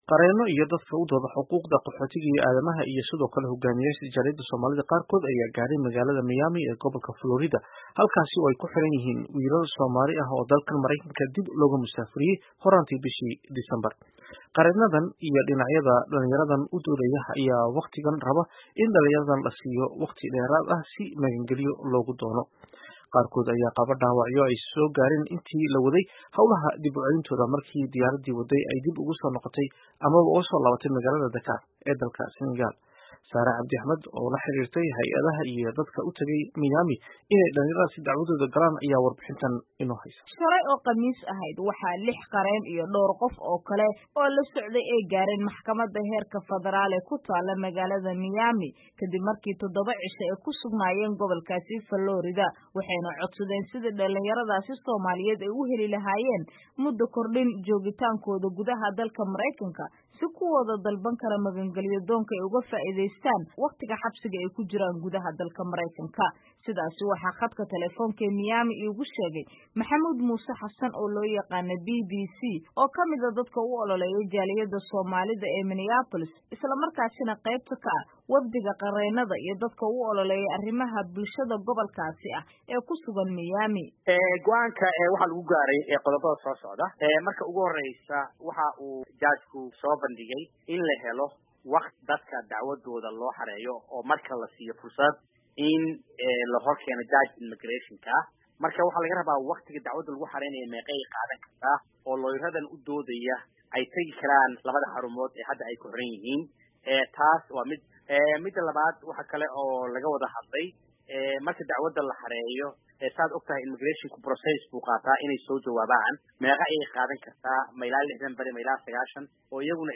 Dhegayso: Warbixin ku saabsan dadaal lagu badbaadinayo dhalinyaro laga tarxiili rabo Maraykanka